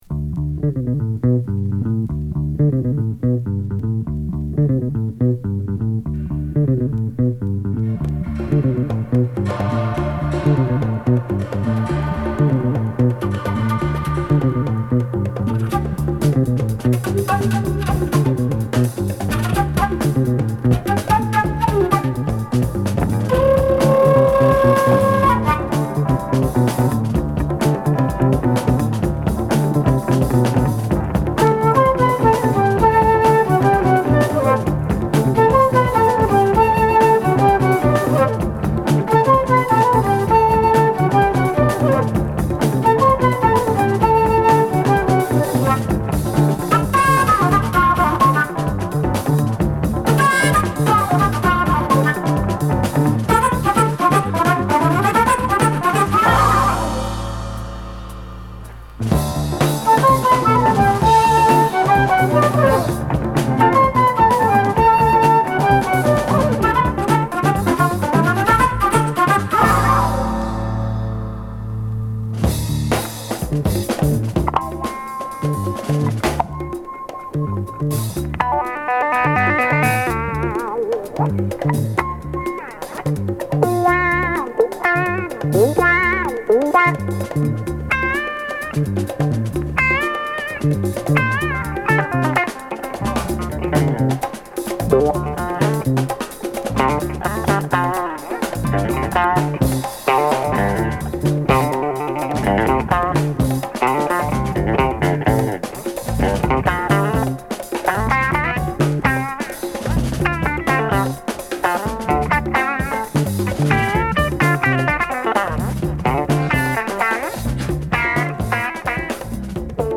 スウェーデンのフルート奏者